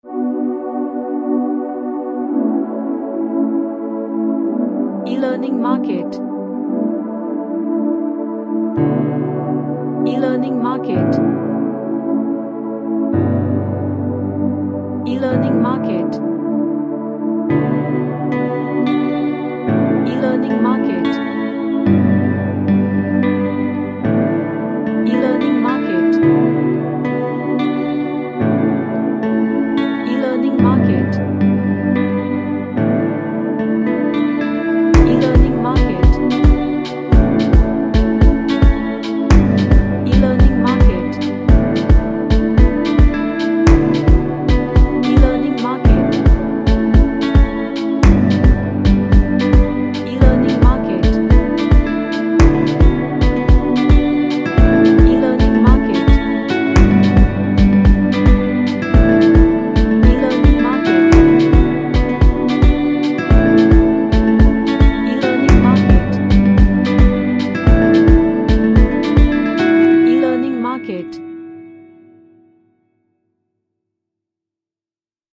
Ambient track with guiate melody.
Relaxation / Meditation